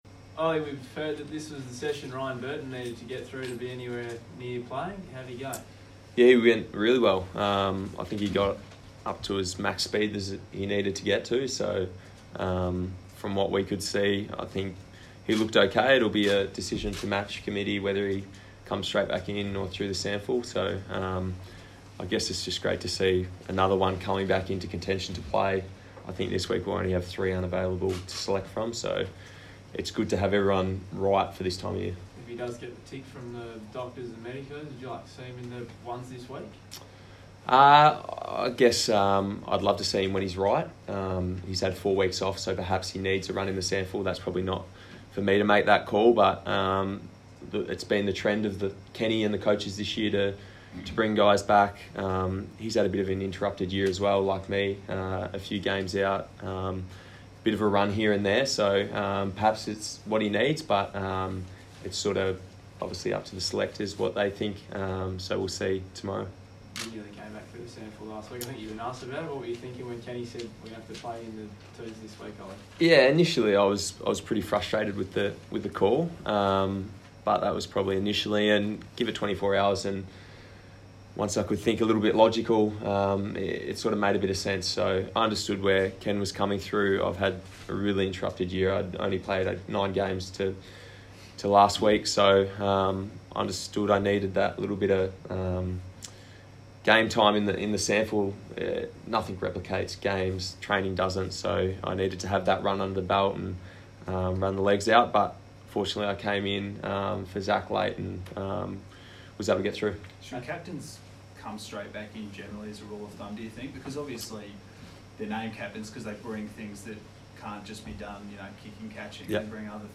Ollie Wines press conference - Wednesday 14 August, 2019